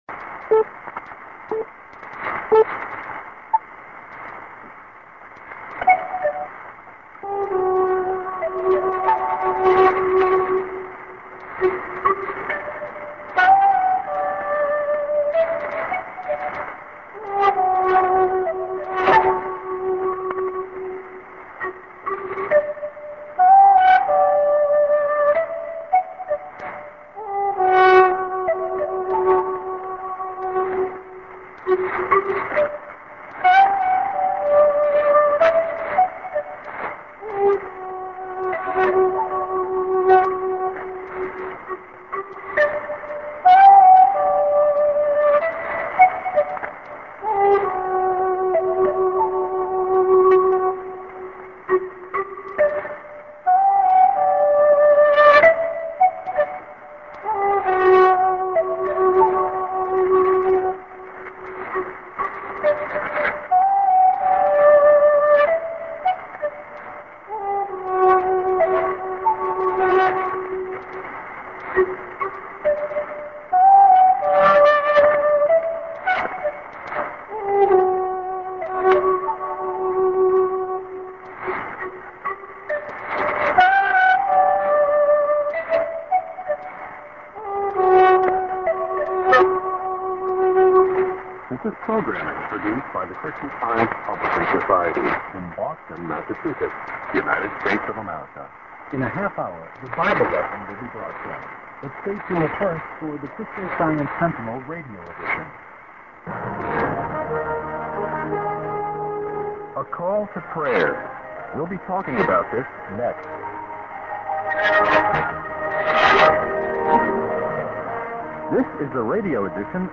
St.TS->IS->ID(man)->ID(man) | Via Taipei